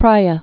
(prīə)